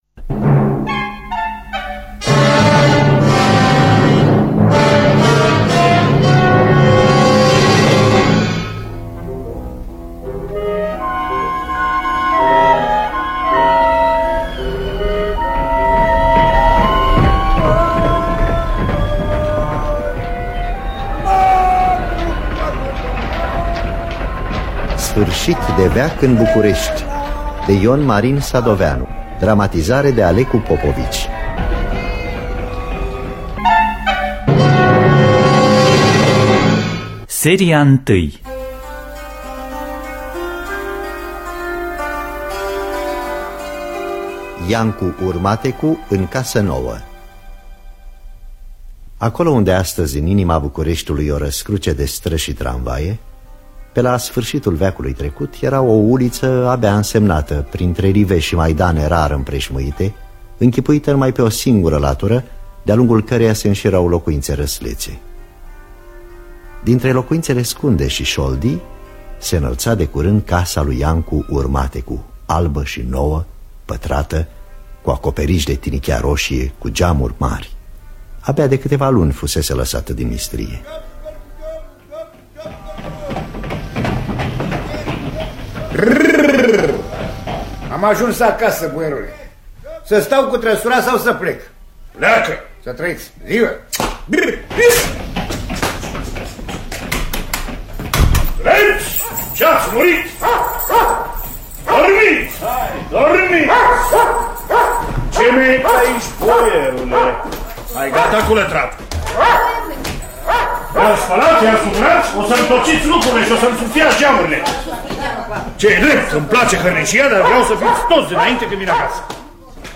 Dramatizarea radiofonică de Alecu Popovici.